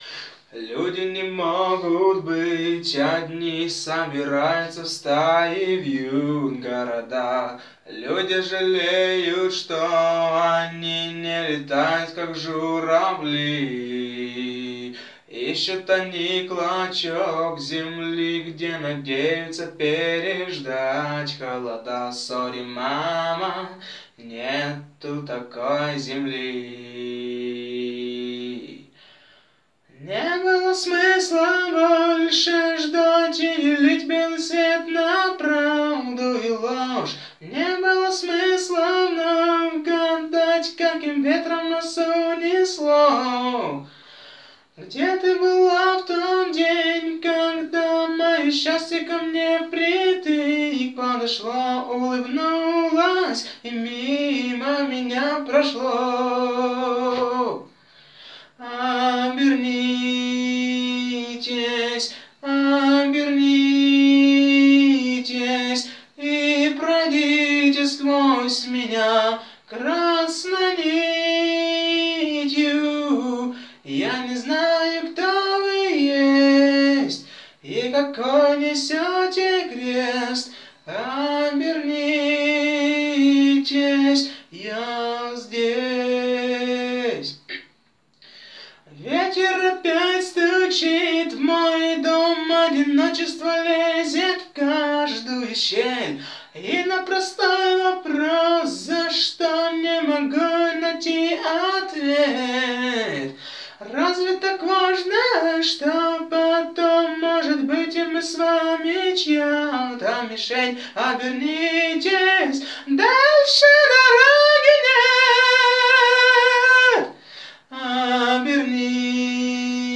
ôîðóì âîêàëèñòîâ îáåðíèòåñü - ñïåë ãðóäíûì ìèêñòîì!!!!
çàïèñàë â âàííîé îäíèì äóáëåì
Èíòîíèðîâàíèå îê ïî÷òè âñþ òåññèòóðó èìåííî íà âîò ýòîé ìèêñîâî-ôàëüöåòíîé.
ñïåë íîðì áðî íî ýòî ôàëüöåò
Ïðîäûõ ôàëüöåòíûé - ñòî ïóäîâî åñòü, à çâóê î÷åíü íåîäíîçíà÷åí ìåñòàìè.
Âîçìîæíî ïîìîãëî òî, ÷òî ïåë ñ ëàäîíüþ ó óõà.